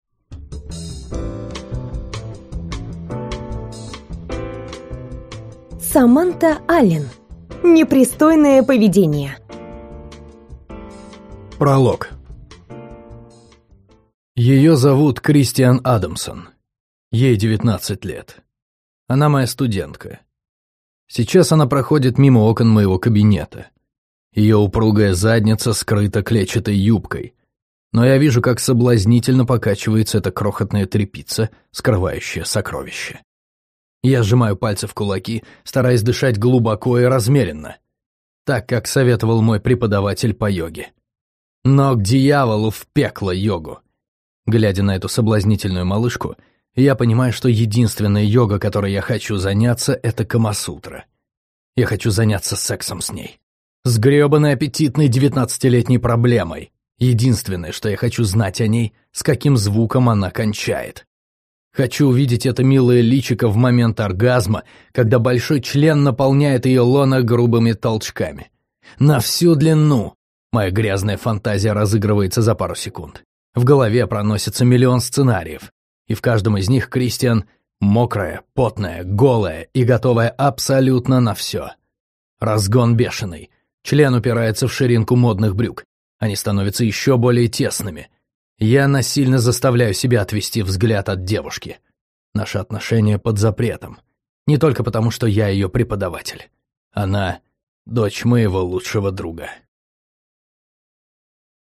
Аудиокнига Непристойное поведение | Библиотека аудиокниг
Прослушать и бесплатно скачать фрагмент аудиокниги